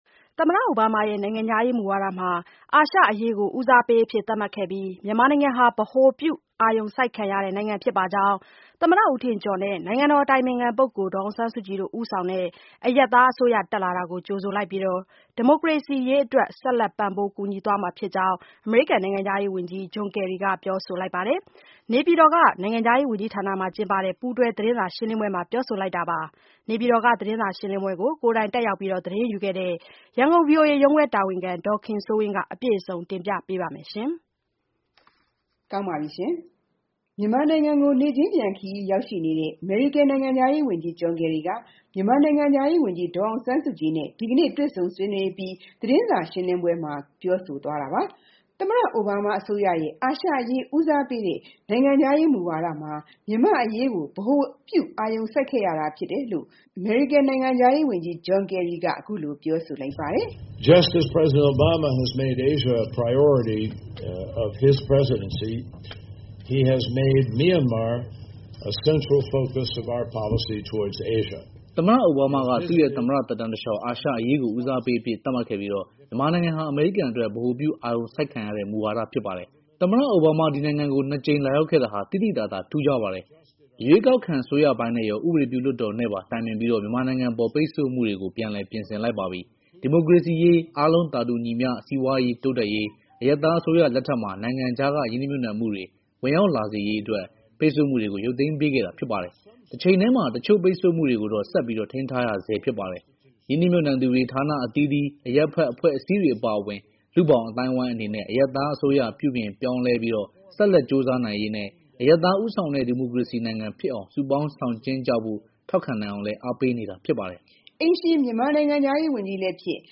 နေပြည်တော်ရှိ နိုင်ငံခြားရေးဝန်ကြီးဌာနမှာ ကျင်းပတဲ့ ပူးတွဲသတင်းစာ ရှင်းလင်းပွဲမှာ ပြောဆိုလိုက်တာပါ။
မြန်မာနိုင်ငံကို နေ့ချင်းပြန်ခရီးရောက်နေတဲ့ အမေရိကန်နိုင်ငံခြားရေးဝန်ကြီး John Kerry က မြန်မာနိုင်ငံခြား ရေးဝန်ကြီး ဒေါ်အောင်ဆန်းစုကြည်နဲ့ ဒီကနေ့ တွေ့ဆုံဆွေးနွေးပြီး သတင်းစာရှင်းလင်းပွဲမှာ ပြောဆိုသွားတာပါ။ သမ္မတအိုဘားမားအစိုးရရဲ့ အာရှအရေး ဦးစားပေးတဲ့ နိုင်ငံခြားရေးမူဝါဒမှာ မြန်မာ့အရေးကို ဗဟိုပြု အာရုံစိုက် ခဲ့ရတာဖြစ်တယ်လို့ အမေရိကန်နိုင်ငံခြားရေးဝန်ကြီး John Kerry က အခုလိုပြောဆိုလိုက်ပါတယ်။